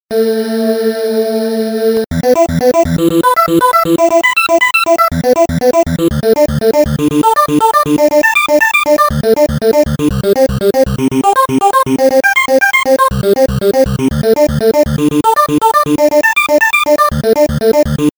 HEAR sequenced samples